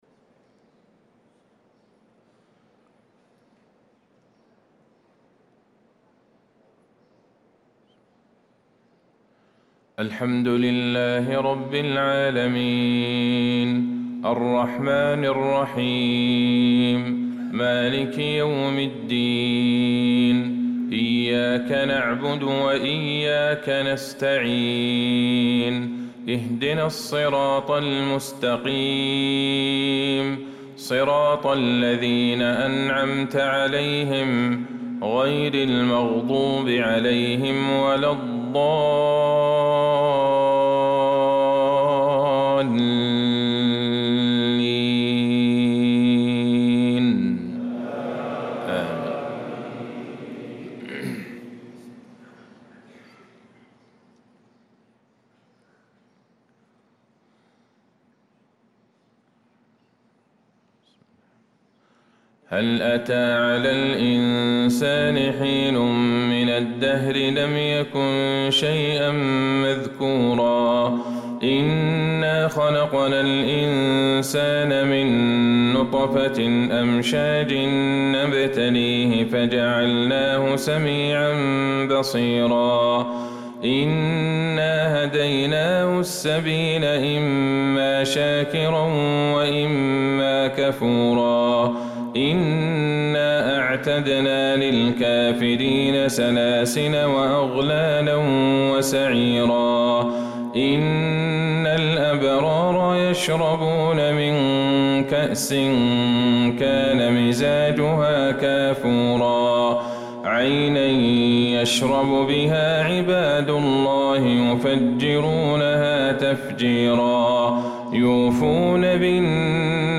صلاة الفجر للقارئ عبدالله الجهني 27 صفر 1446 هـ
تِلَاوَات الْحَرَمَيْن .